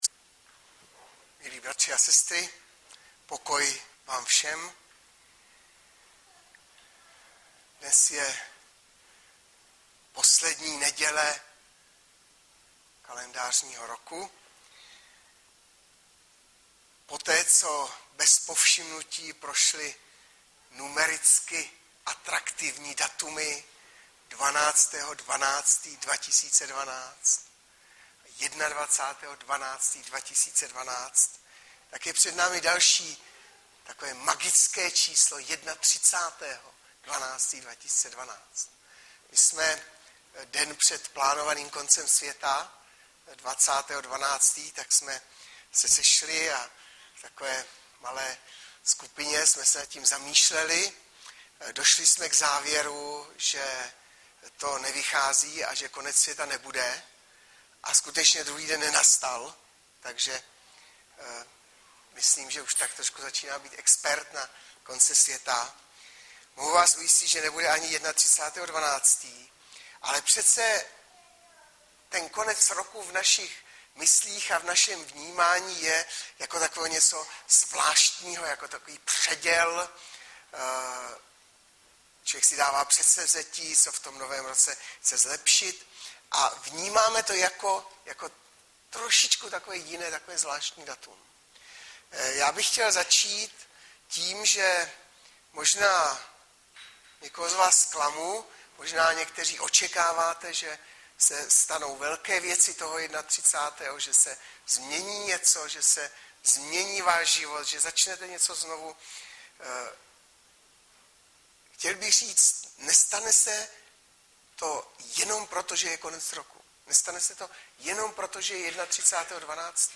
Sam 7,1-12 Audiozáznam kázání si můžete také uložit do PC na tomto odkazu.